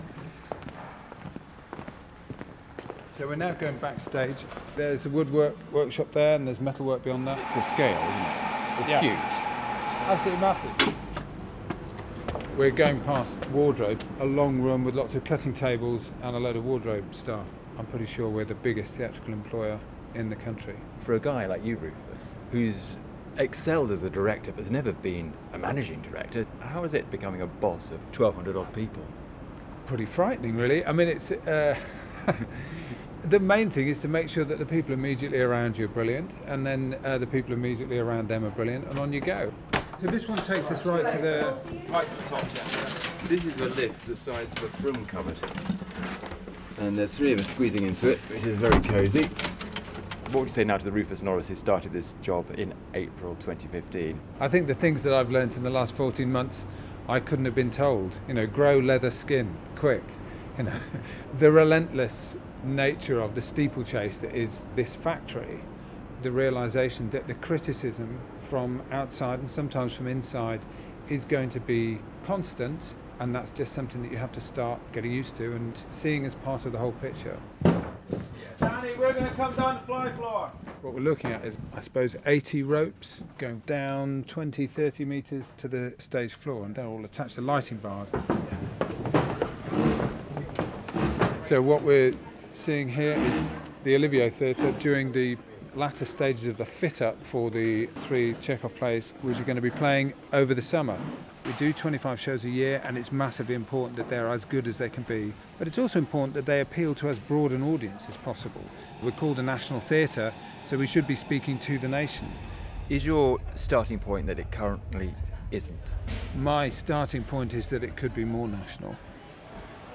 Artistic director of the National Theatre, Rufus Norris shows BBC Arts Editor Will Gompertz around the building and talks about his vision and how tough it is at the top. Today, BBC Radio 4.